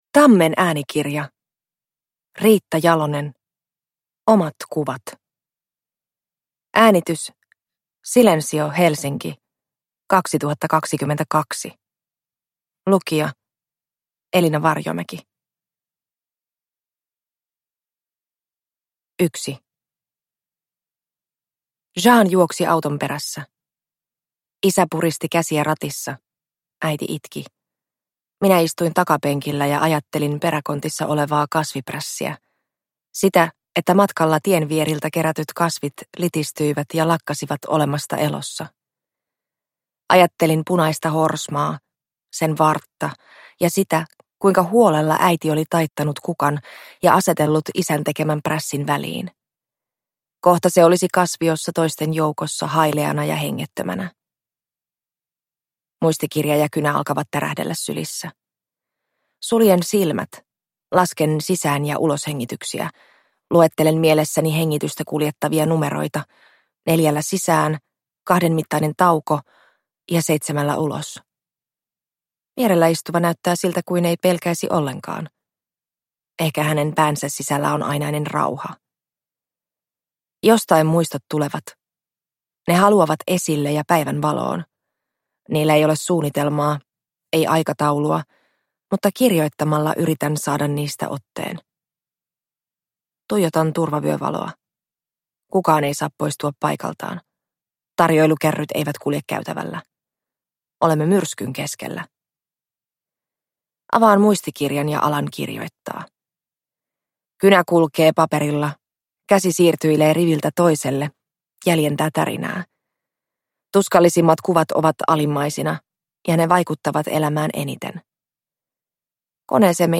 Omat kuvat – Ljudbok – Laddas ner